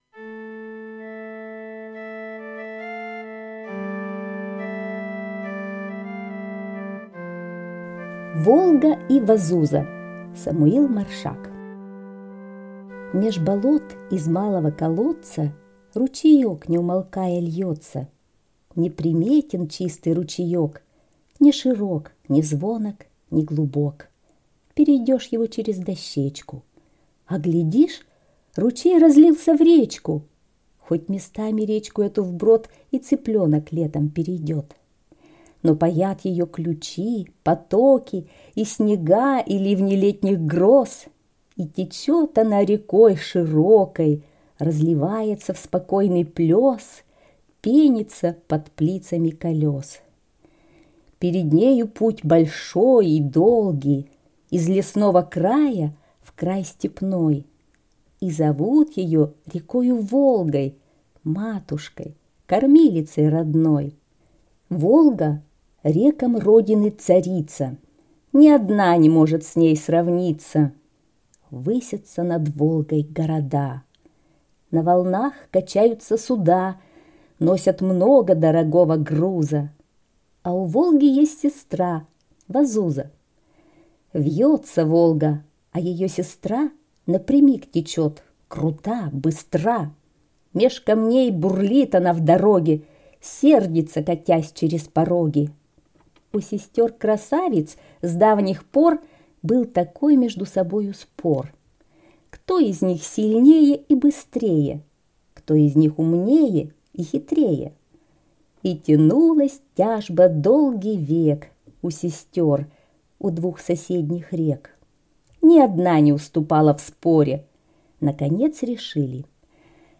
Волга и Вазуза - аудиосказка Маршака С.Я. Сказка про то, как реки Волга и Вазуза спорили, кто из них сильнее и быстрее, умнее и хитрее.